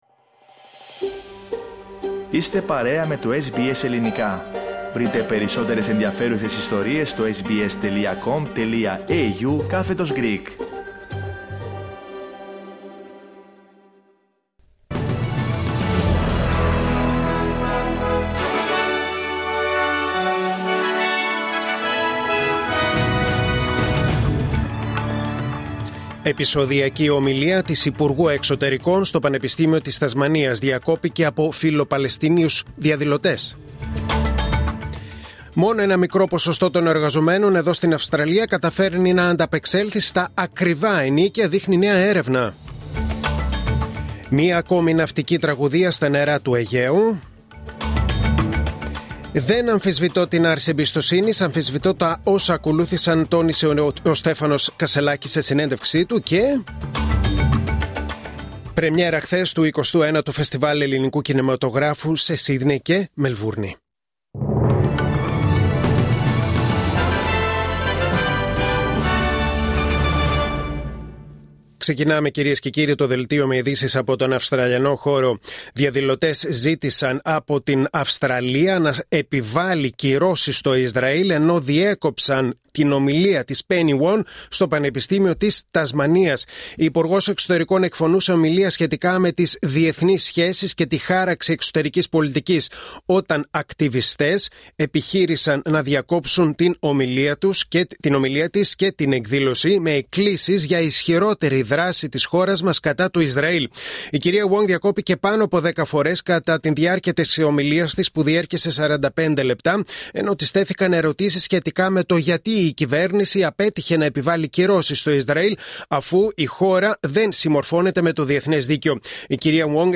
Δελτίο Ειδήσεων Τετάρτη 16 Οκτωβρίου 2024